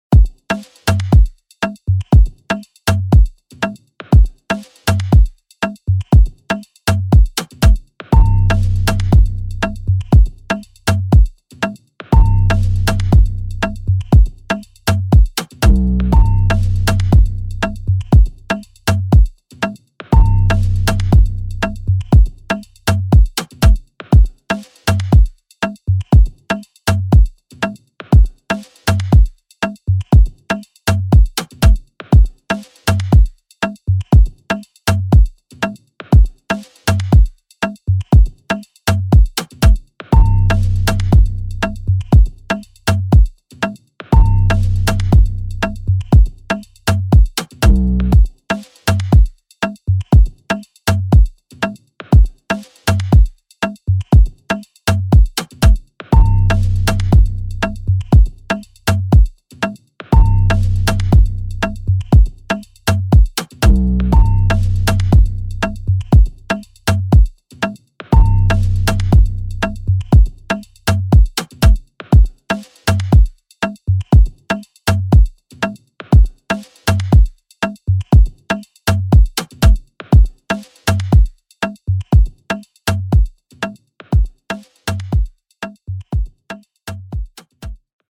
2023 in Dancehall/Afrobeats Instrumentals